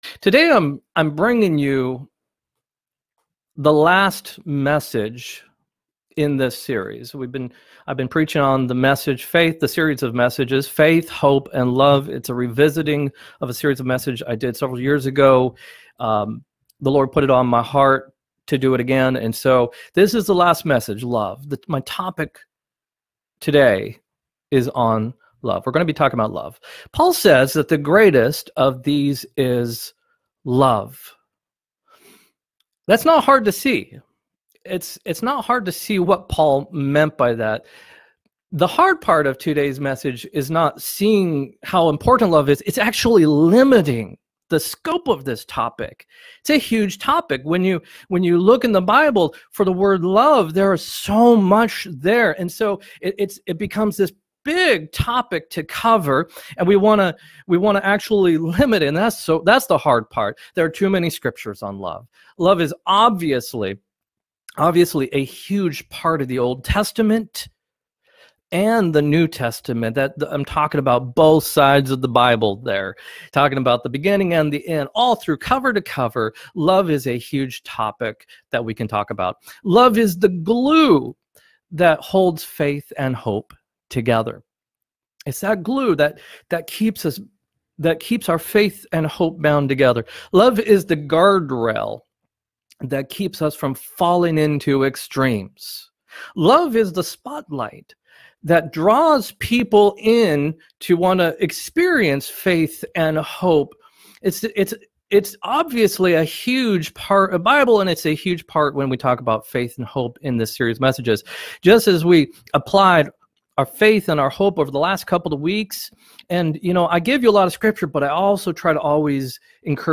4-18-20 sermon-a
4-18-20-sermon-a.m4a